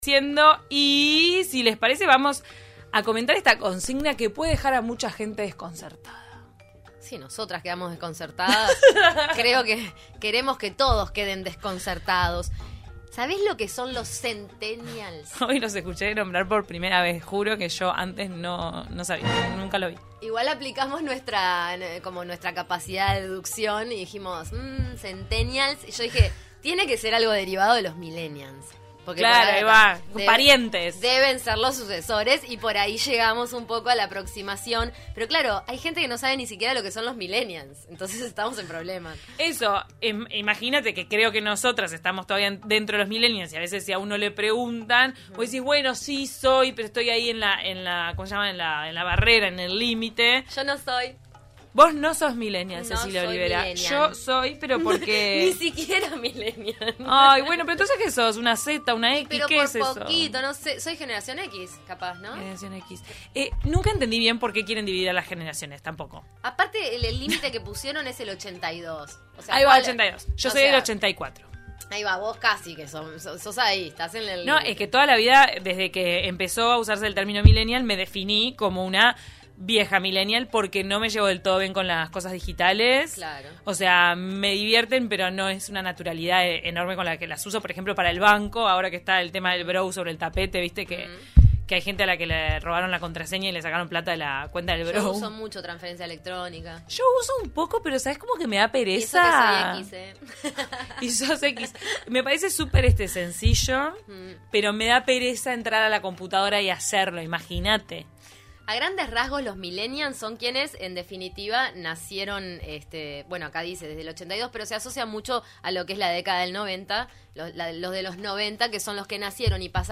A partir de esto, De taquito a la mañana reflexionó sobre ambas generaciones y sus características, y estuvo en la calle preguntándole a la gente: para vos, ¿qué son y qué caracteriza a los millenials?